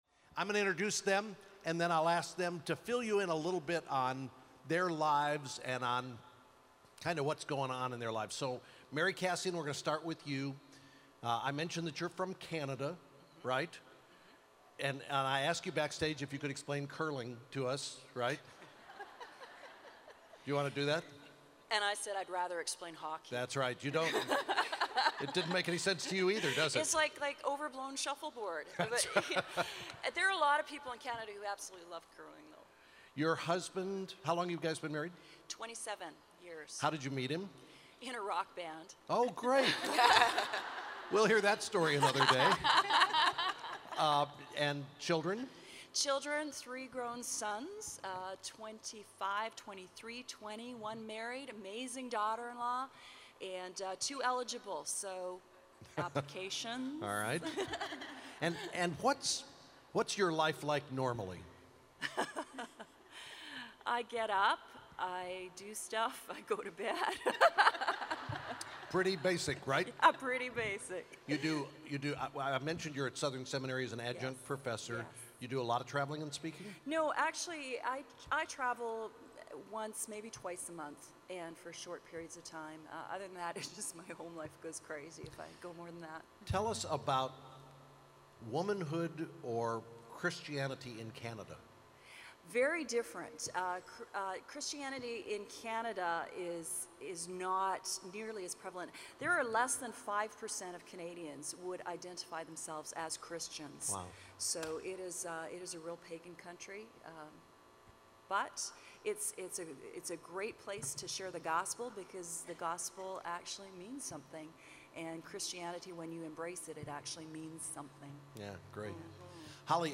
Living Out the True Woman Message | True Woman '10 Chattanooga | Events | Revive Our Hearts
tw_chattanooga_saturday_panel.mp3